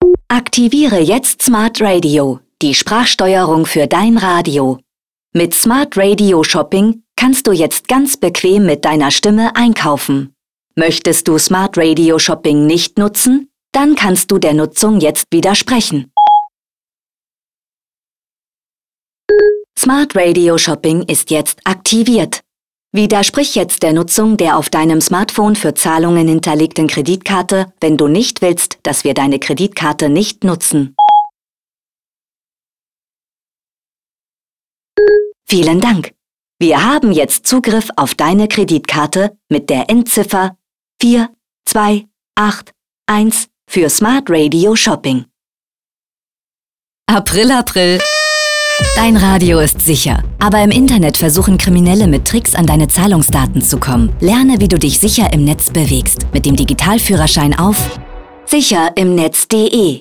Dazu werden Hörer:innen von einem vermeintlich “smarten Radio” mit Spracherkennung, im Stile von Amazons Alexa oder Apples Siri reingelegt, um sie in den Bereichen Onlinebanking, Dating und Shopping auf Risiken im Internet aufmerksam zu machen.